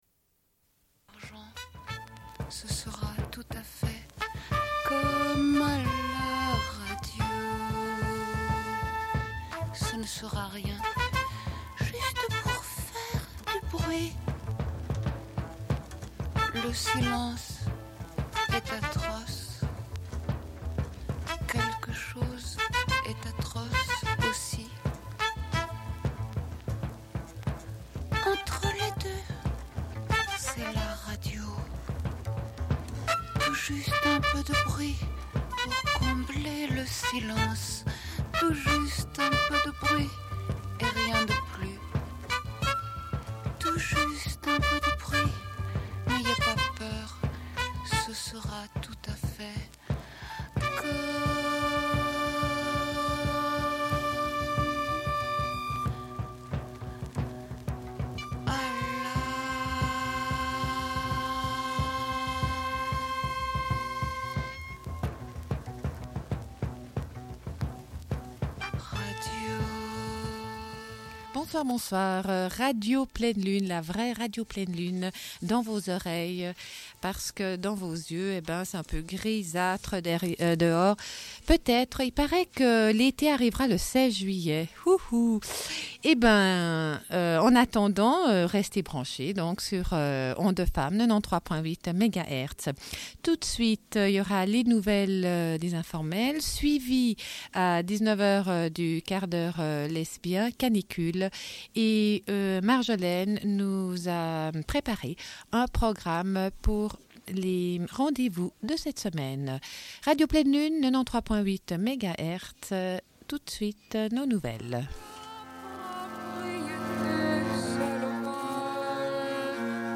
Bulletin d'information de Radio Pleine Lune du 24.06.1992 - Archives contestataires